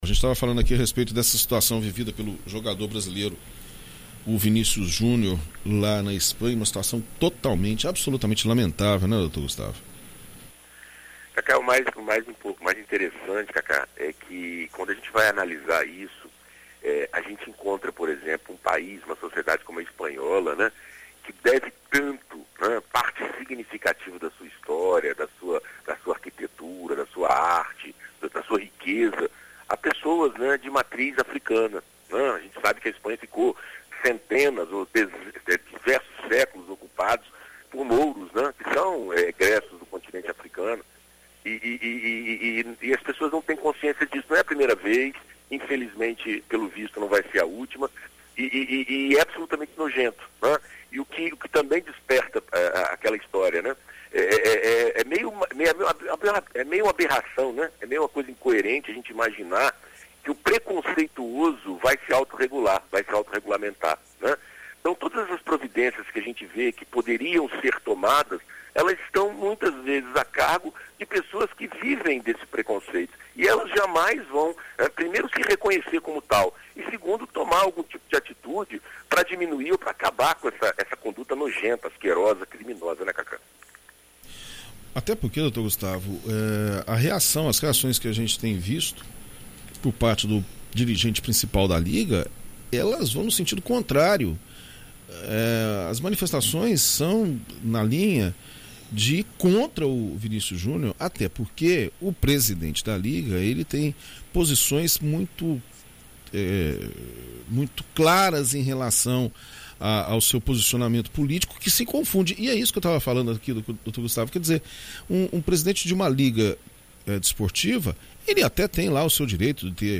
Na coluna desta segunda-feira (22) na BandNews FM Espírito Santo